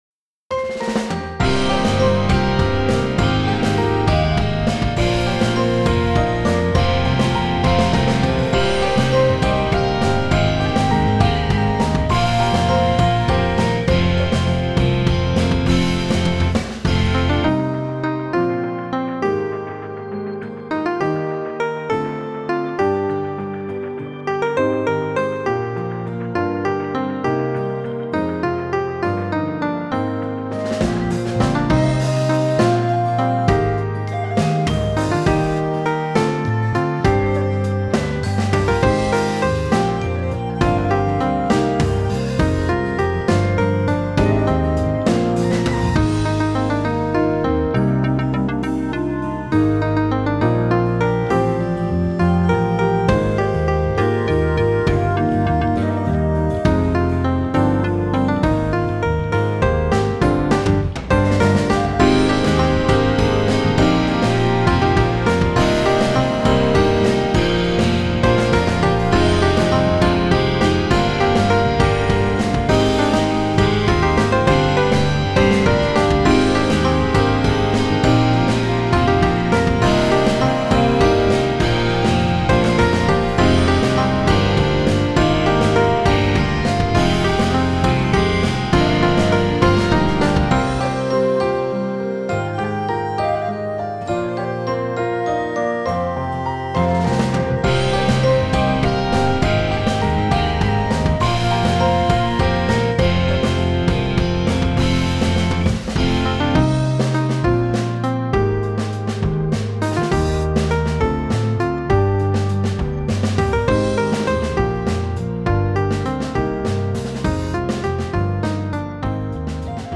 で、せっかくですのでこの辺りで出来上がったラフの中から1曲ご試聴